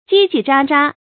唧唧喳喳 注音： ㄐㄧ ㄐㄧ ㄓㄚ ㄓㄚ 讀音讀法： 意思解釋： 形容雜亂尖細的聲音 出處典故： 蕭紅《回憶魯迅先生》：「院子里的小孩們 唧唧喳喳 地玩耍著。」